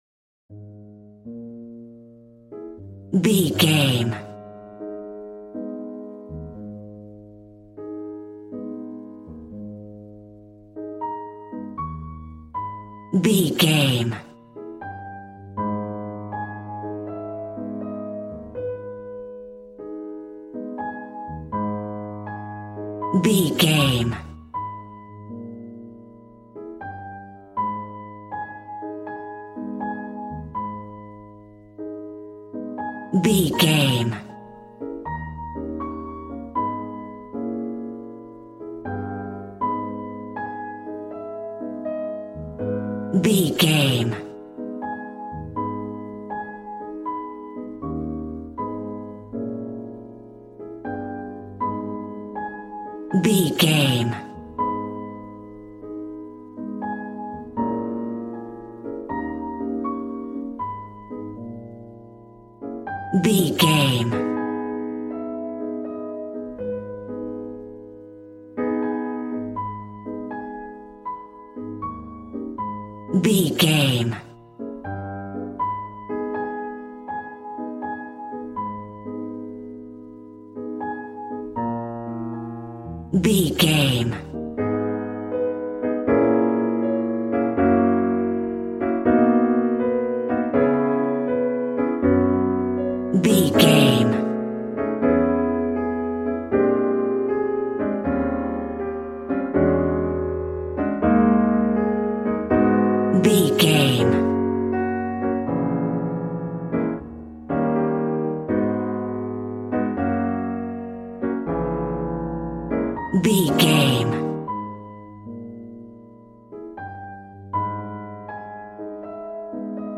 Smooth jazz piano mixed with jazz bass and cool jazz drums.,
Aeolian/Minor
A♭
piano
drums